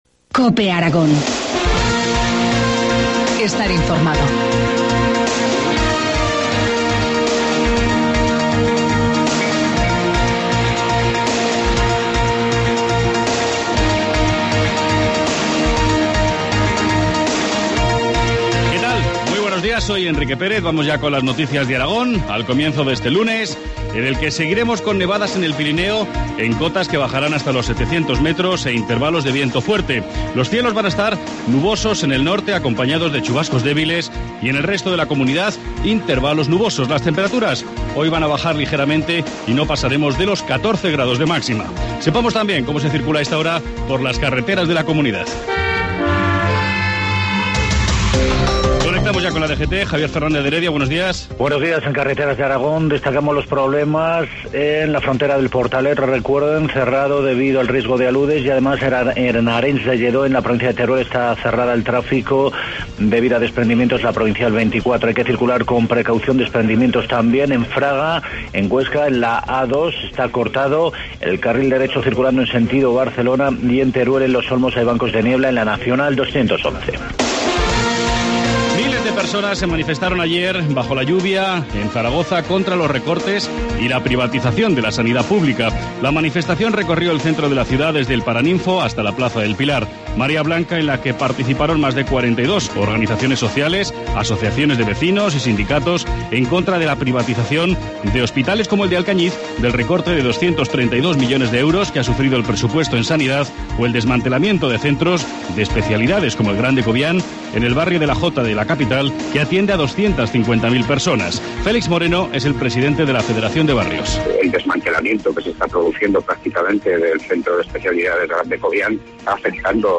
Informativo matinal, lunes 18 de marzo, 7.25 horas